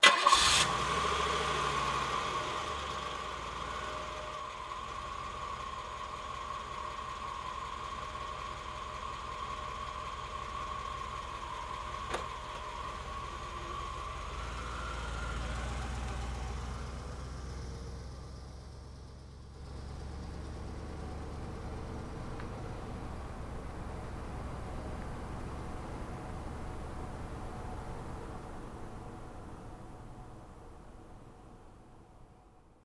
Cadillac 1990 Exterior Start, Idle, Pull Away Very Slow